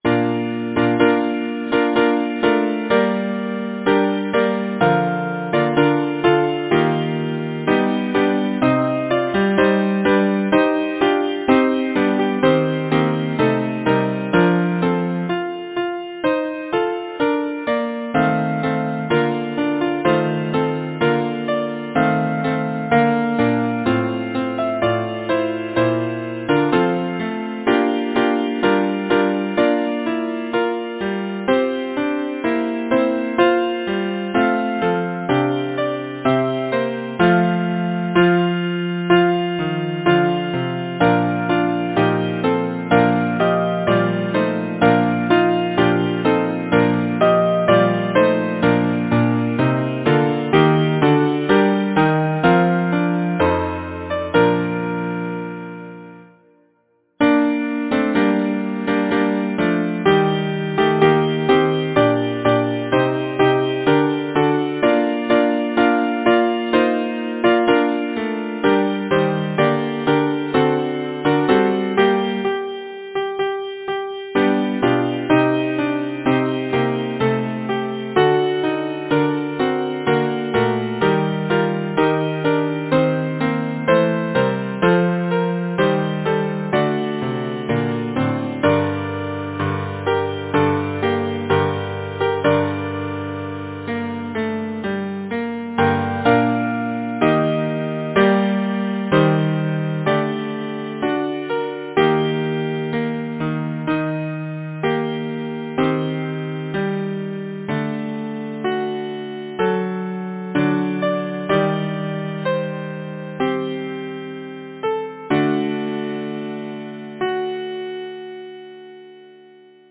Number of voices: 4vv Voicing: SATB Genre: Secular, Partsong
Instruments: A cappella